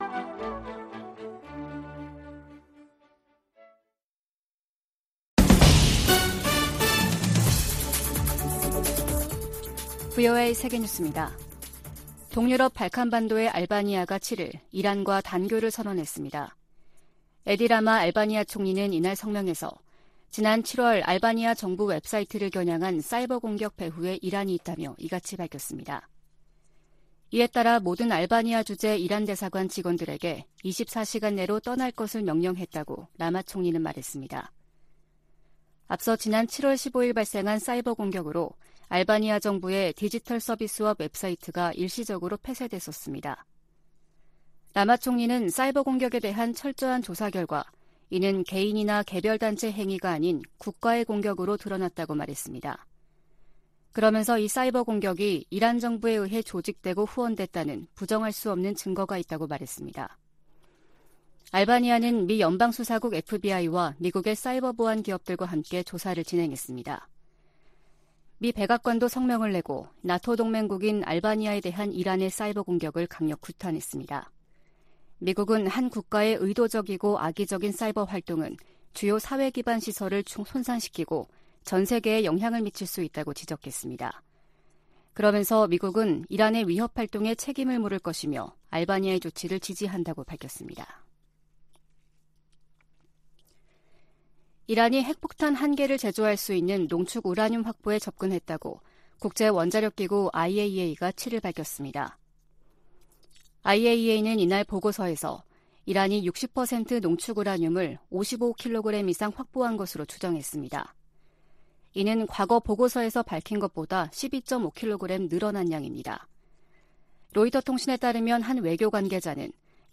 VOA 한국어 아침 뉴스 프로그램 '워싱턴 뉴스 광장' 2022년 9월 8일 방송입니다. 미국과 한국, 일본 북핵 수석대표들이 북한의 도발에 단호히 대응하겠다고 거듭 강조했습니다. 미국 정부가 러시아의 북한 로켓과 포탄 구매에 대해 유엔 안보리 결의 위반이라고 지적했습니다. 유엔이 강제실종과 관련해 북한에 총 362건의 통보문을 보냈지만 단 한 건도 응답하지 않았다며 유감을 나타냈습니다.